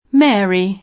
Mary [ingl. m $ ëri ]